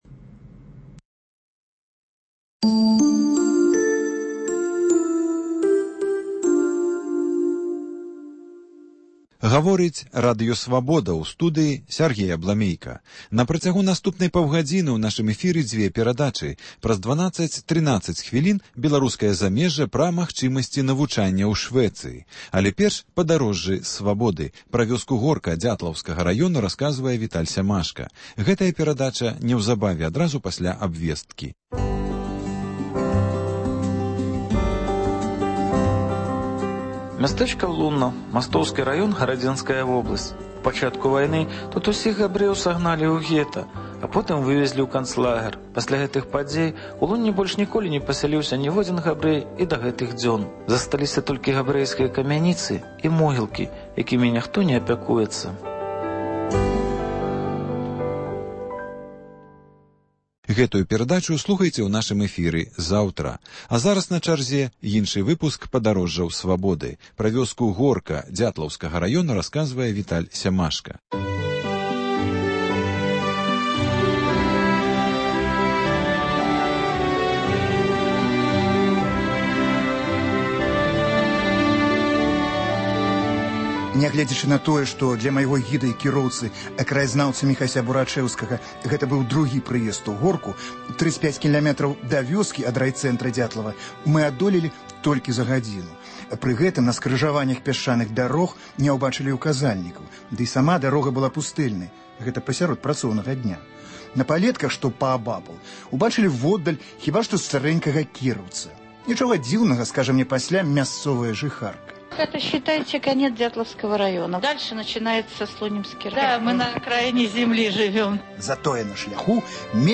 Паездкі нашых карэспандэнтаў па гарадах і вёсках Беларусі: вёска Горка Дзятлаўскага раёну.